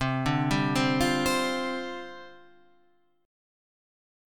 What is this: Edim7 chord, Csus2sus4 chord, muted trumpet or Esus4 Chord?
Csus2sus4 chord